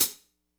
hihat02.wav